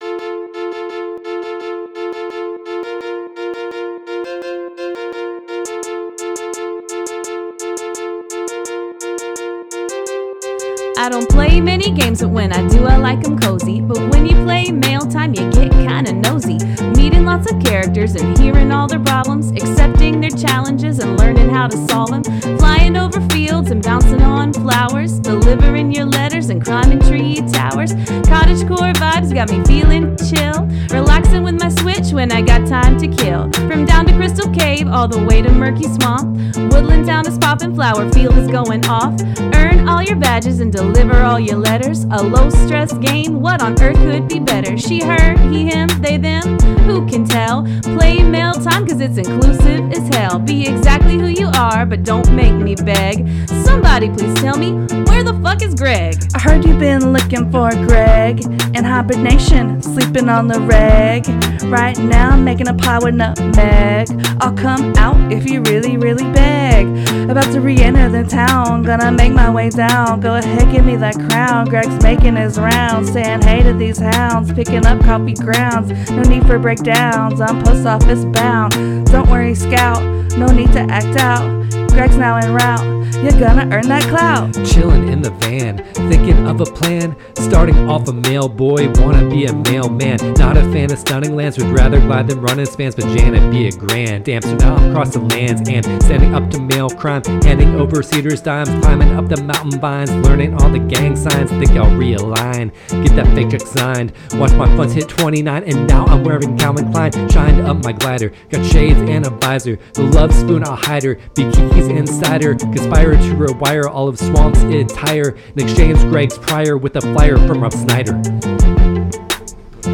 Rap from Episode 66: Mail Time – Press any Button
Mail-Time-Rap.mp3